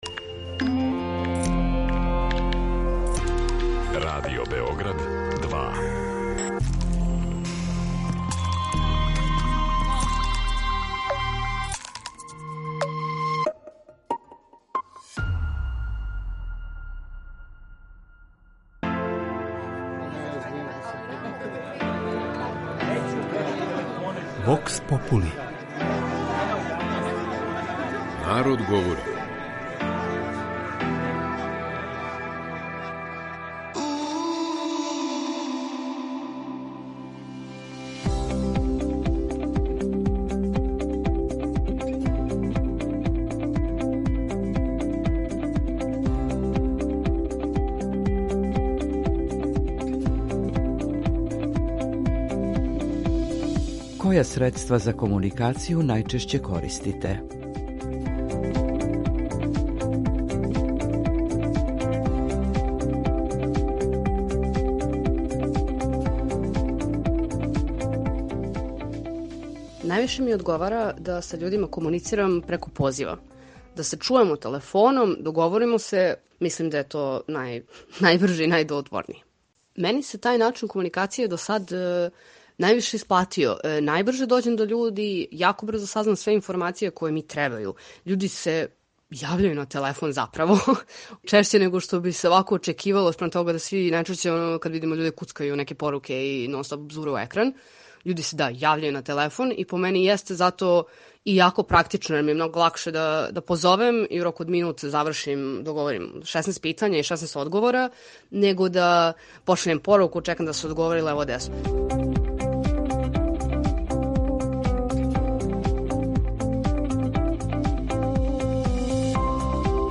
У данашњој емисији питали смо наше суграђане која средства комуникације најчешће користе.